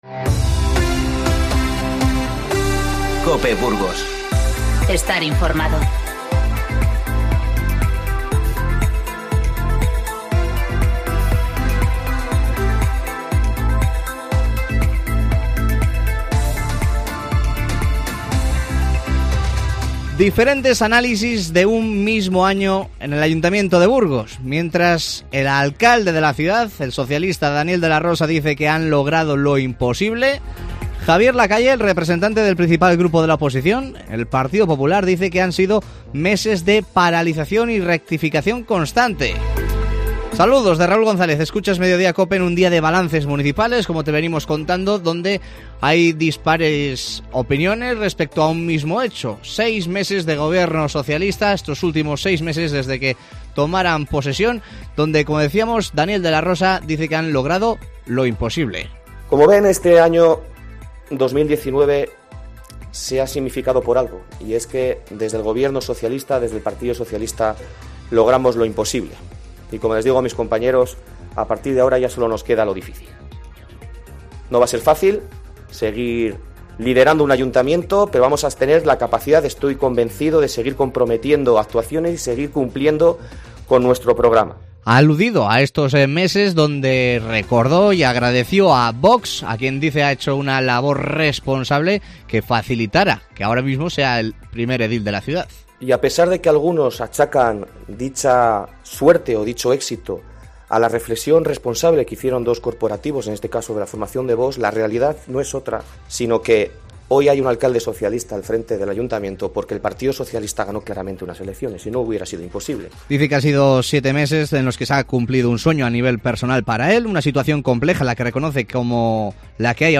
INFORMATIVO Mediodía 30-12-19